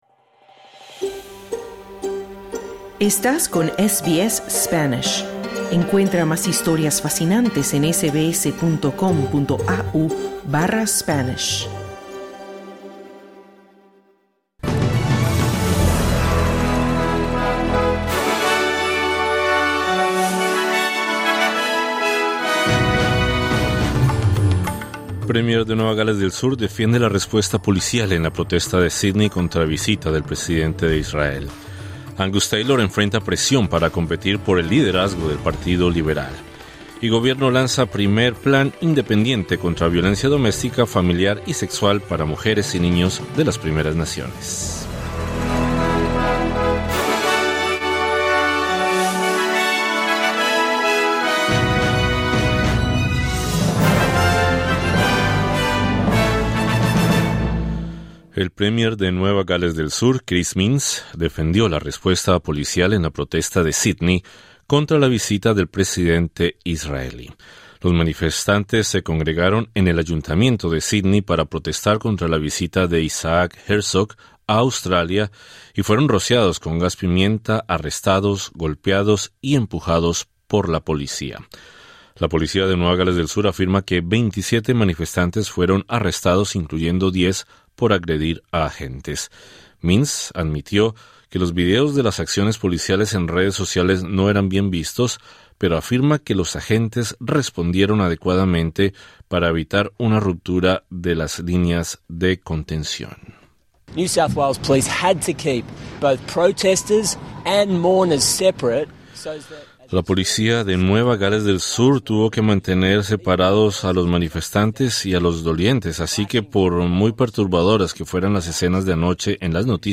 Boletín 10/02/2026: El premier de Nueva Gales del Sur Chris Minns defendió la respuesta policial en la protesta de Sídney contra la visita del presidente israelí. Escucha el resumen informativo de la jornada.